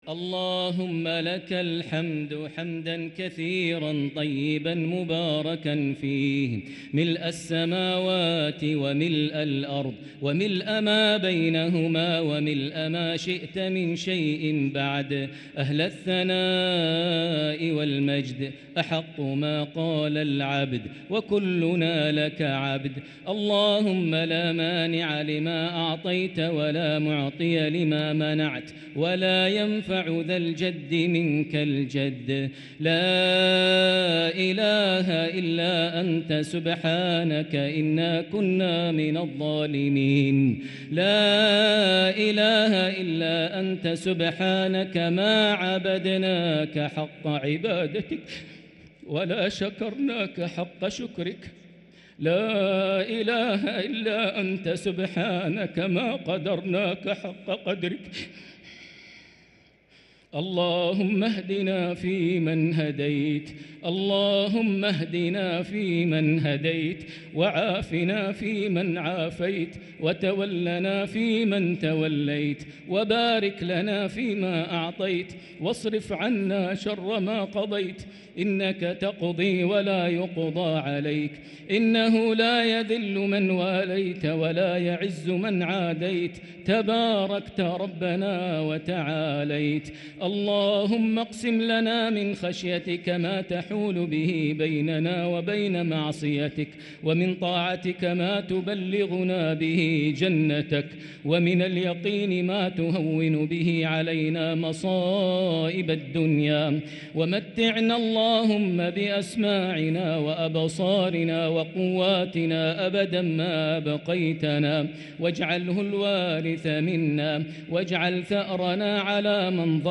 دعاء القنوت ليلة 5 رمضان 1444هـ > تراويح 1444هـ > التراويح - تلاوات ماهر المعيقلي